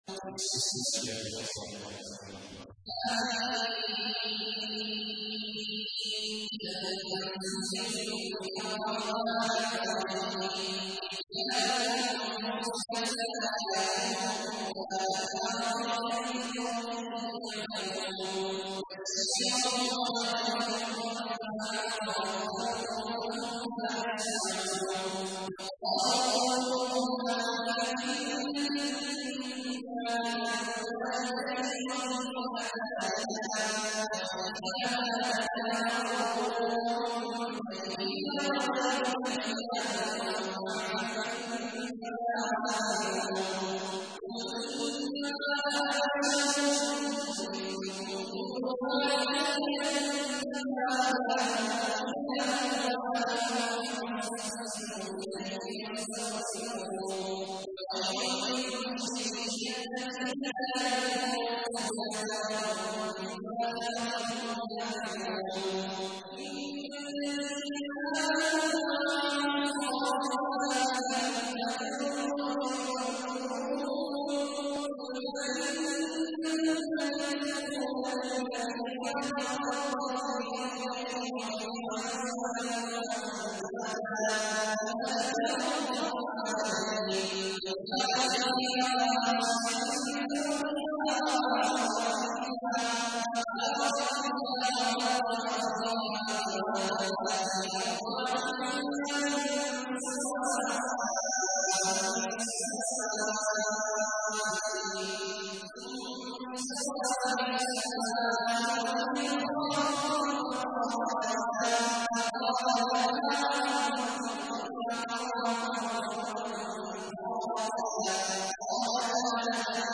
تحميل : 41. سورة فصلت / القارئ عبد الله عواد الجهني / القرآن الكريم / موقع يا حسين